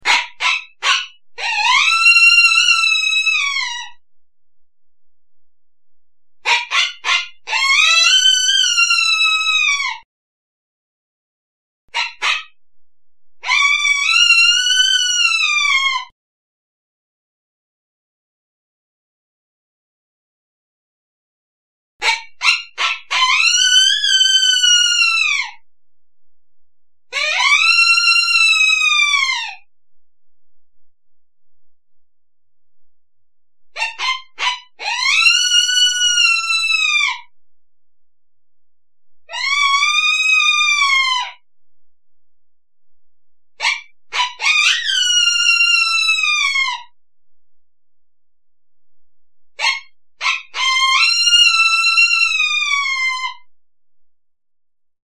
Койота поймали и посадили в клетку, теперь он жалобно скулит